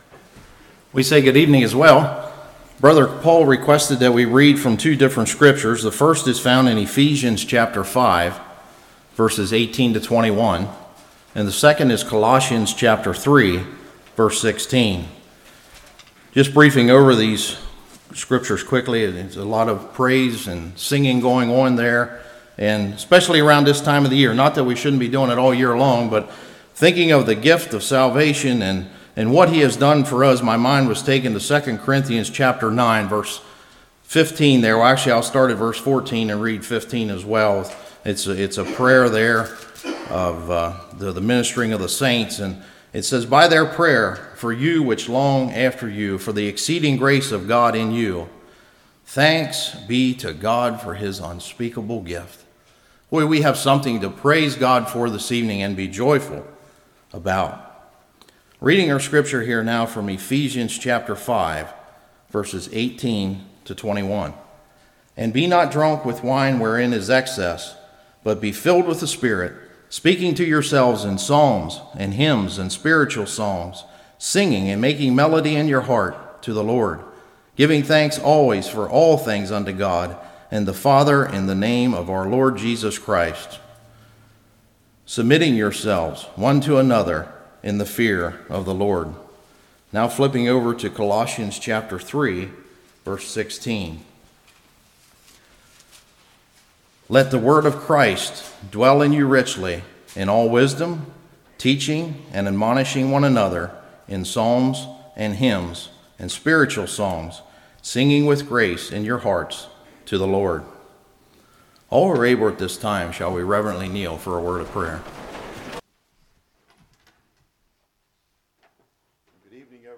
Colossians 3:16 Service Type: Evening Psalms